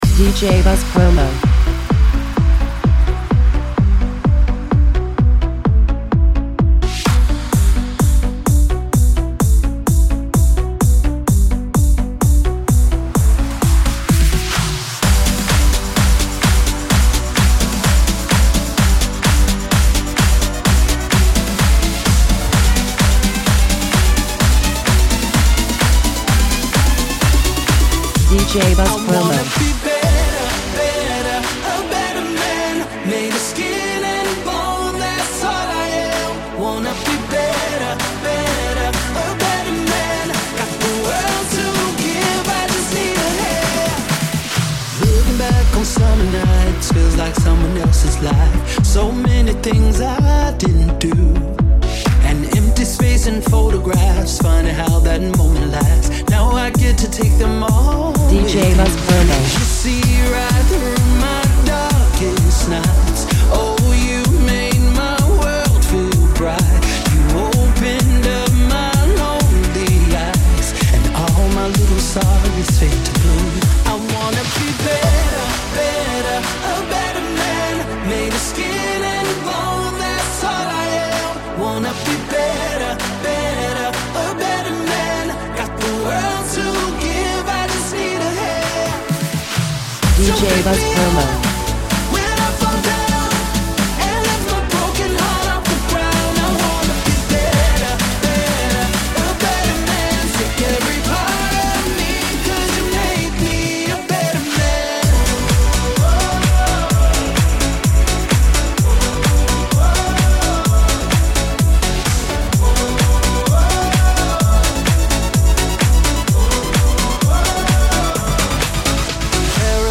the energetic and uplifting